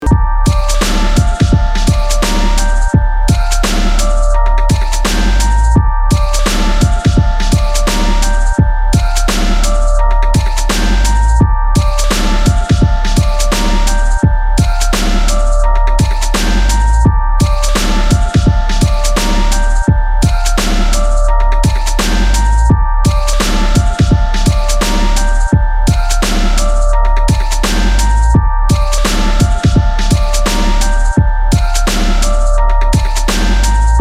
• Качество: 320, Stereo
без слов
Bass
instrumental hip-hop
beats
минус